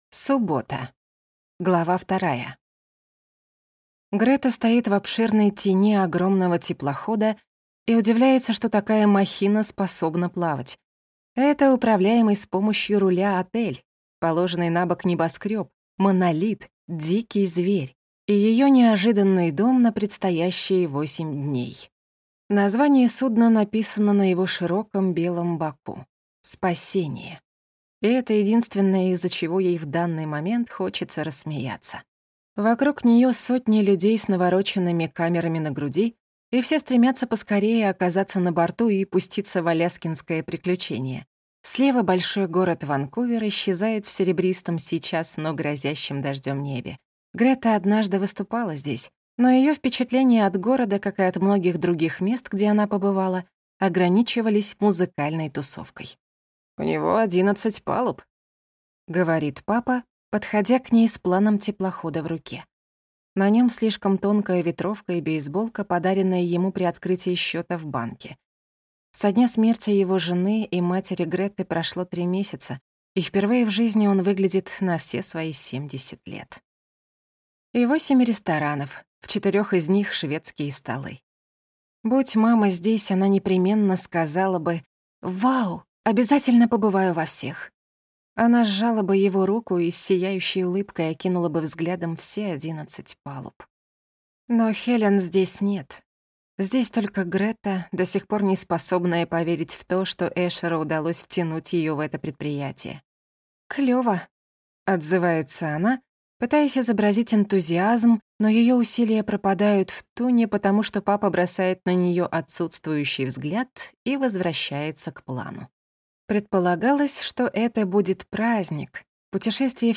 Аудиокнига Непотопляемая Грета Джеймс | Библиотека аудиокниг